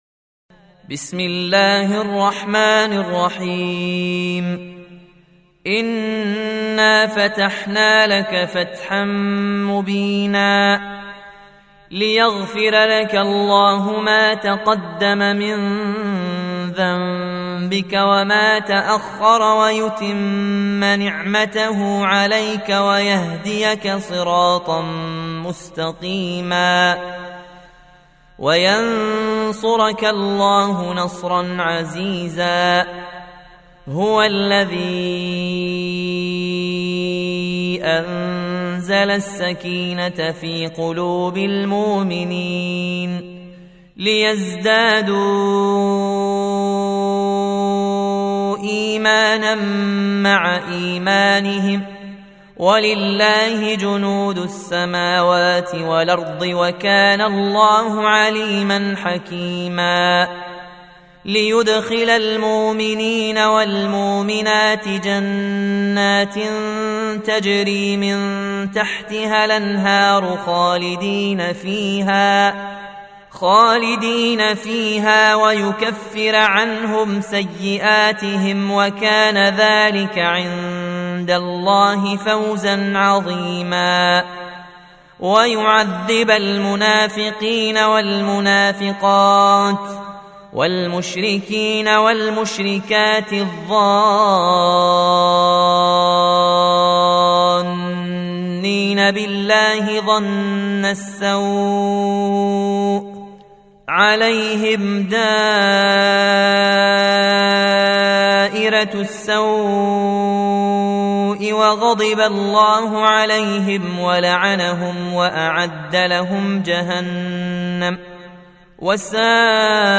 Surah Sequence تتابع السورة Download Surah حمّل السورة Reciting Murattalah Audio for 48. Surah Al-Fath سورة الفتح N.B *Surah Includes Al-Basmalah Reciters Sequents تتابع التلاوات Reciters Repeats تكرار التلاوات